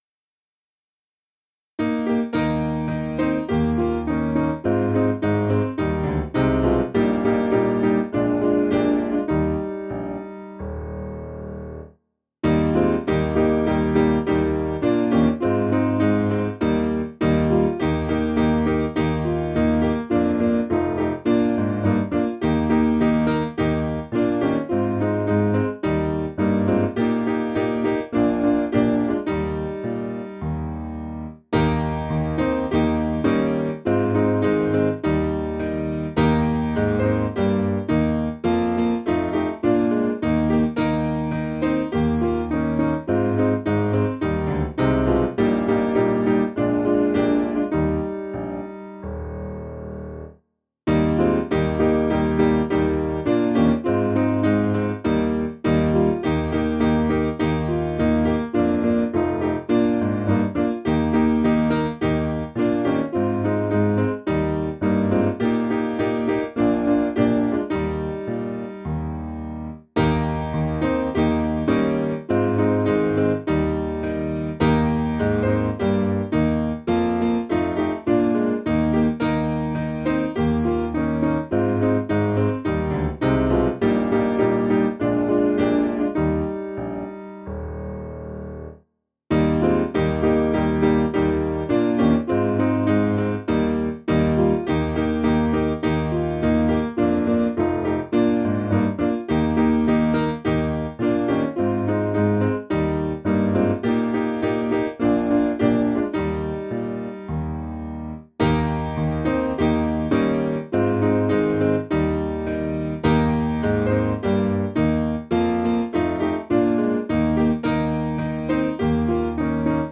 Simple Piano
(CM)   3/Eb 488.7kb